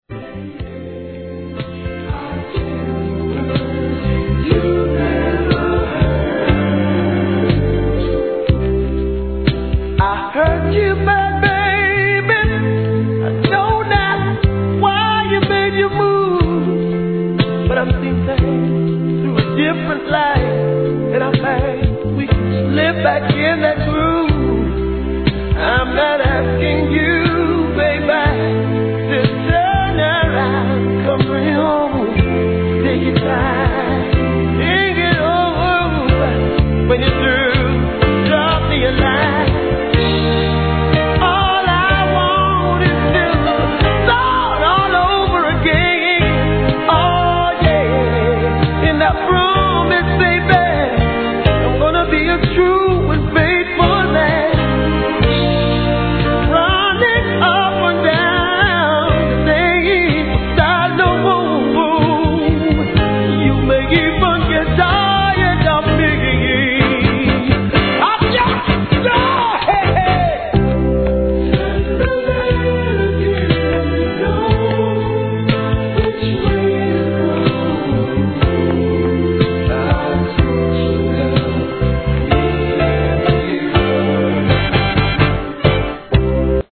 終盤で周期的なノイズが一箇所あります
REGGAE
素晴らしいJAMAICAN SOUL♪